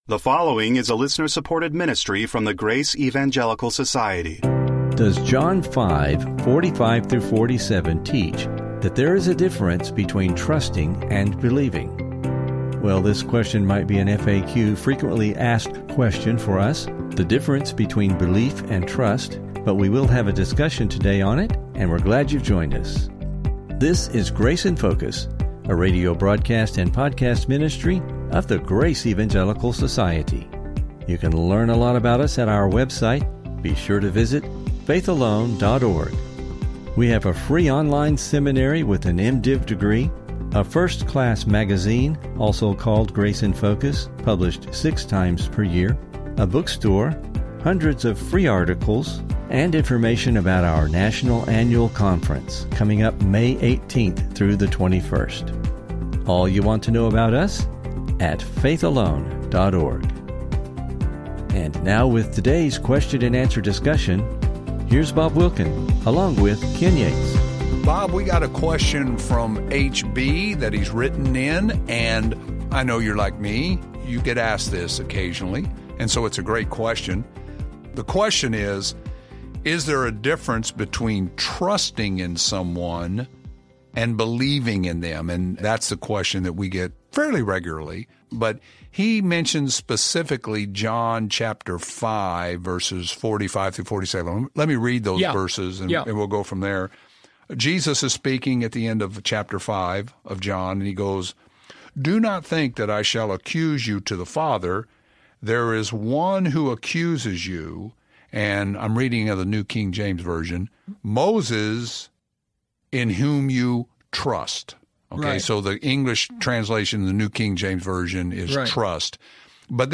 And how about in Greek and in English, how do they compare and contrast? Please listen for an informative discussion and never miss an episode of the Grace in Focus Podcast!